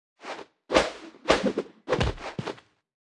Media:anim_street_trader_intro_01.wav 动作音效 anim 查看其技能时触发动作的音效
Anim_street_trader_intro_01.wav